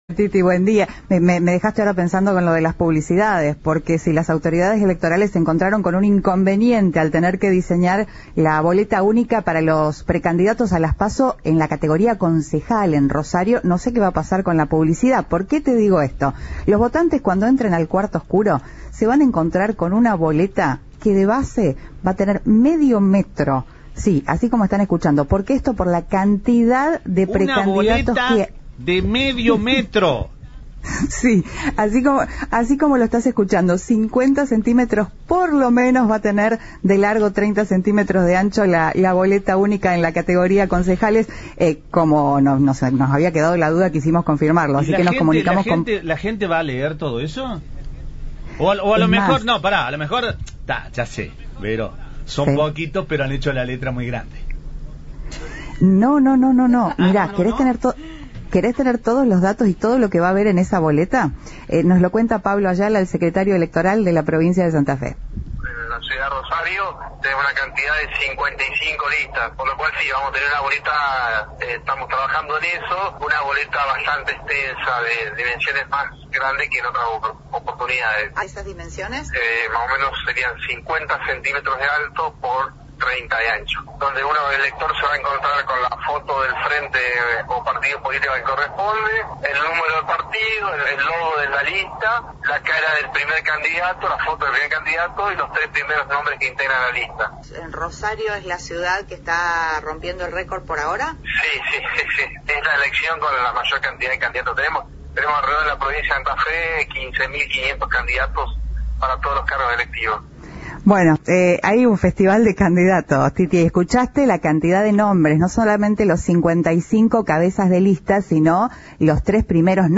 El secretario electoral de la Provincia, Pablo Ayala, detalló a Cadena 3 que el formato será de 50 centímetros de alto y 30 de ancho.